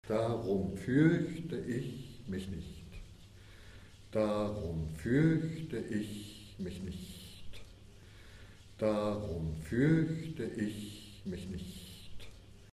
Prononciation
Darum furchte ich mich nicht - chant.mp3